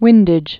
(wĭndĭj)